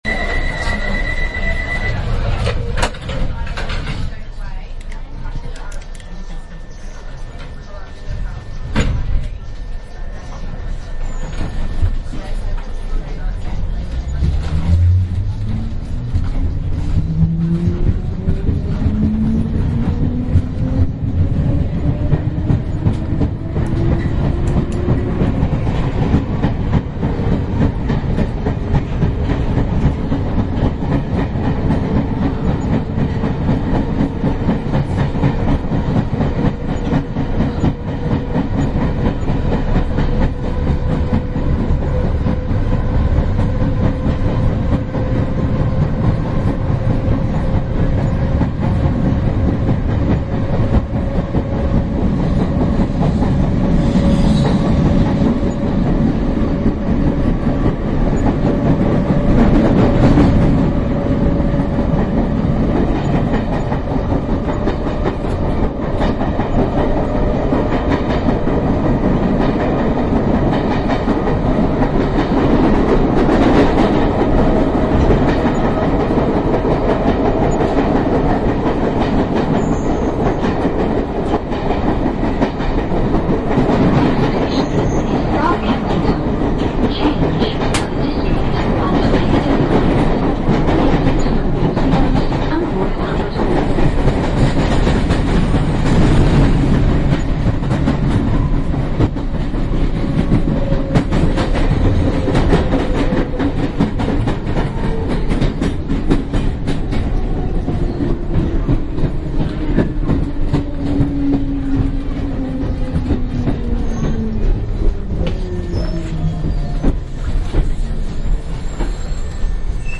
伦敦地铁 " 伦敦地铁车站的地铁列车
描述：在摄政公园站的管火车里面。门打开，轻声交谈，车站宣布和通过巴士到伦敦动物园的连接信息，门关闭警告信息和哔哔声。 2015年2月16日录制第四代iPod touch。
标签： 谈话 到达 地铁 管站 发出蜂鸣声 地铁 语音 摄政公园 伦敦地铁 火车 地铁 现场录音 氛围 公告 贝克鲁线 打开 关闭 管系 伦敦 氛围 离开
声道立体声